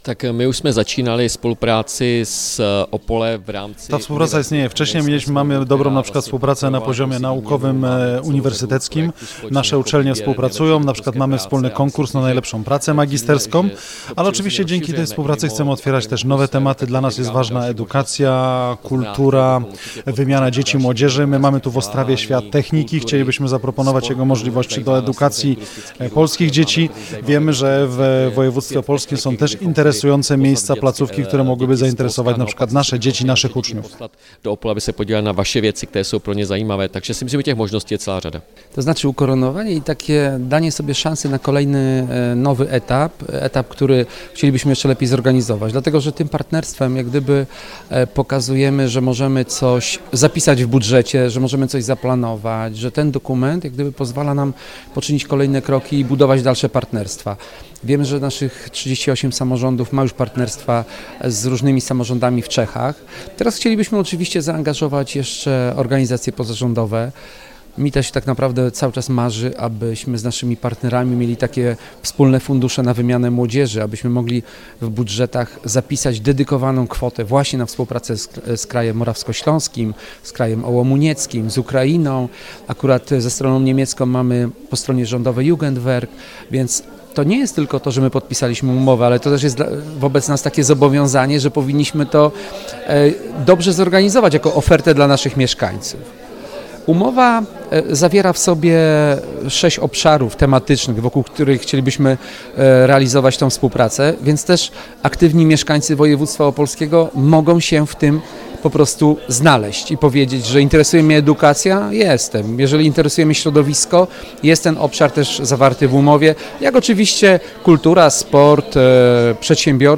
Kraj Morawsko-Śląski oraz Województwo Opolskie oficjalnie partnerami. W Ostrawie 15 września podpisano dokument o wzajemnej współpracy.
Hetman kraju morawsko-śląskiego – Ivo Vondrák – mówił o tym, że najlepsze są partnerstwa bliskie z uwagi na położenie geograficzne.